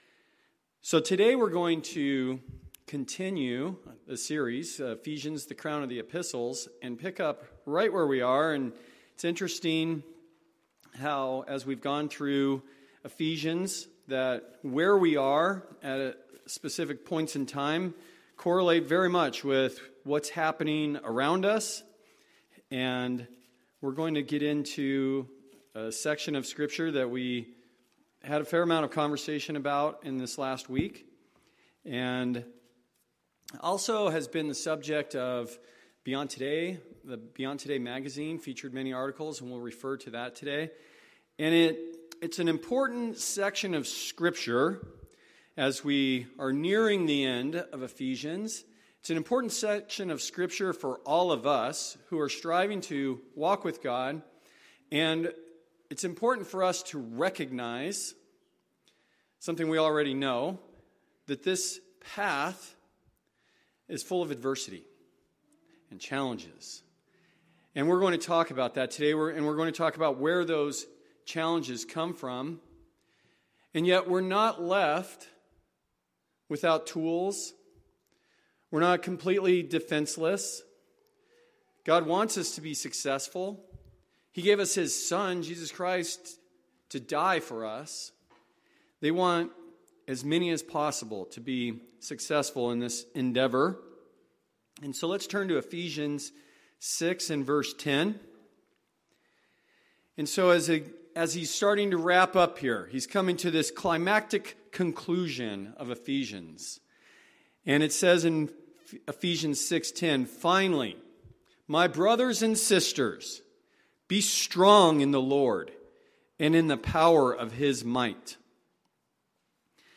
Sermons
Given in Phoenix Northwest, AZ